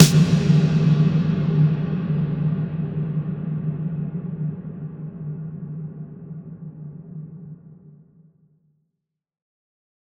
Big Drum Hit 07.wav